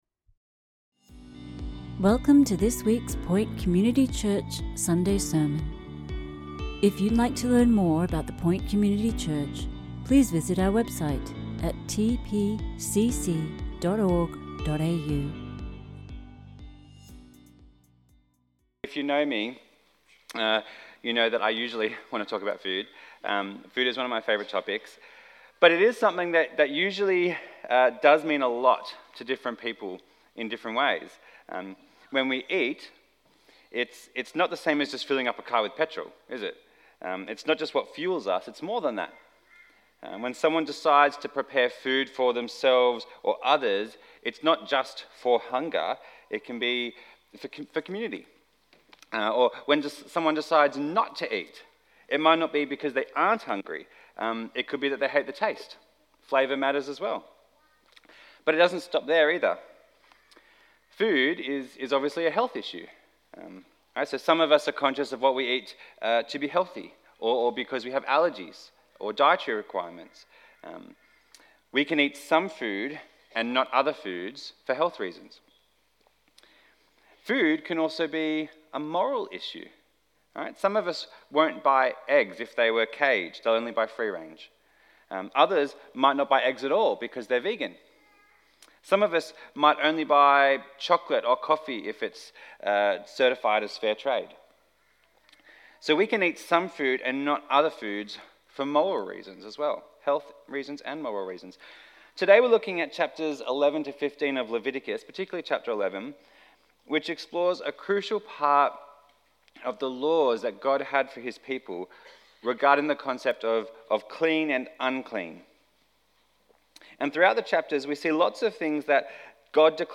Sermons | The Point Community Church